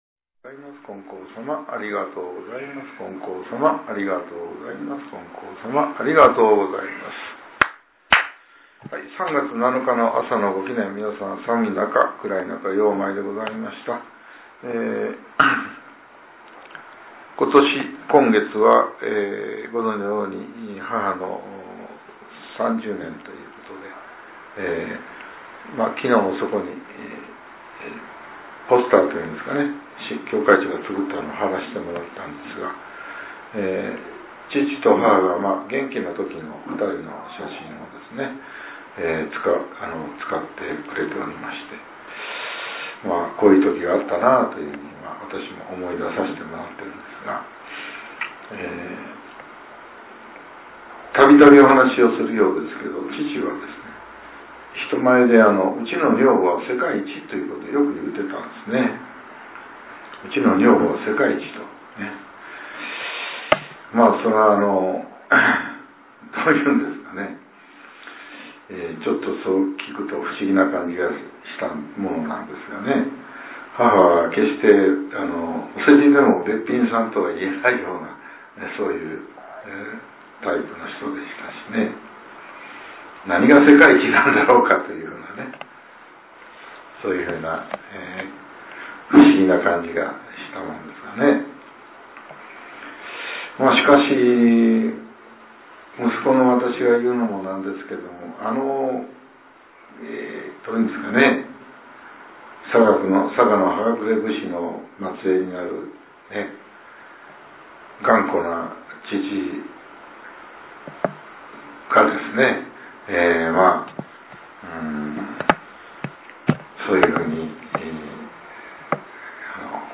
令和８年３月７日（朝）のお話が、音声ブログとして更新させれています。 きょうは、前教会長による「この人であればこそ」です。